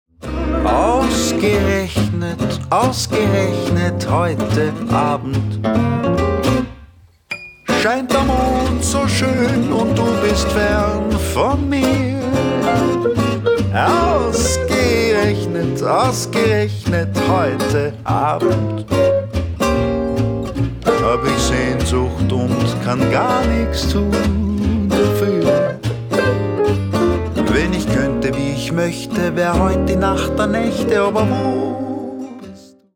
Stromgitarre und Refraingesang